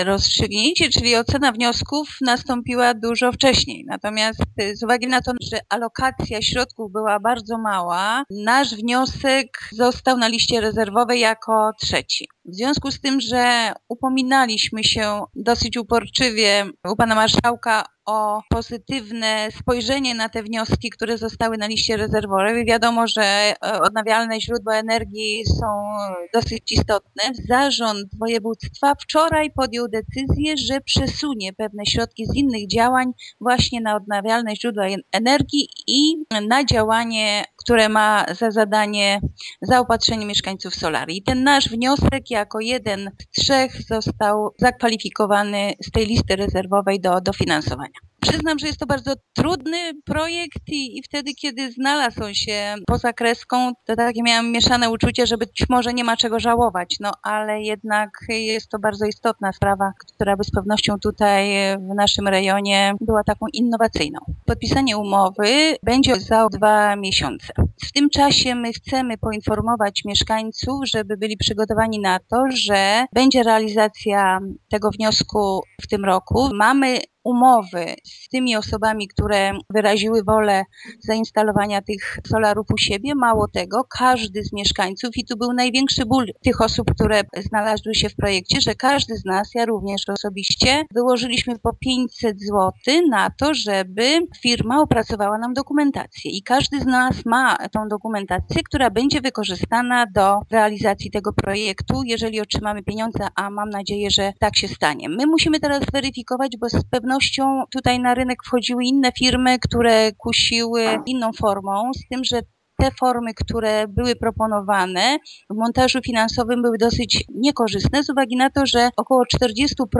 Bogusława Jaworska burmistrz Zwolenia, który jest liderem wspólnego projektu, cieszy się z tej decyzji i przypomina, że starania o unijne wsparcie trwały prawie dwa lata: